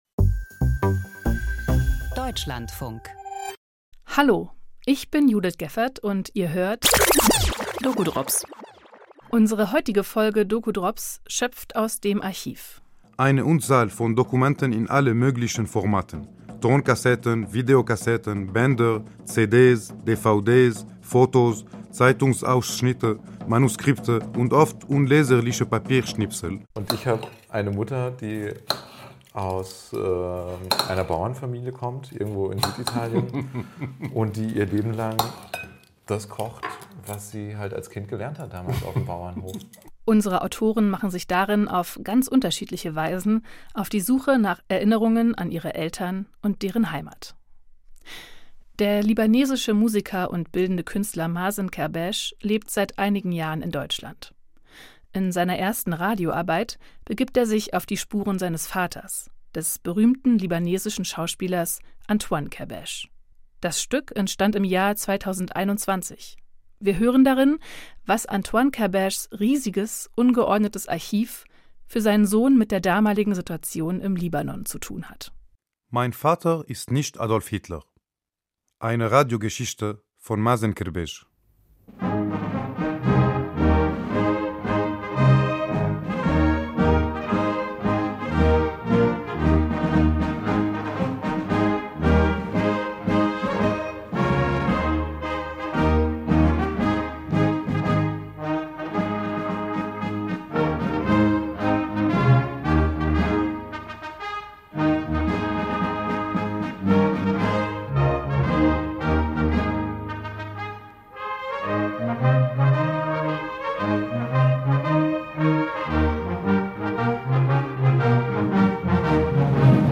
Kurzdokus aus der Feature-Antenne und den doku drops neu gehört und neu gemischt.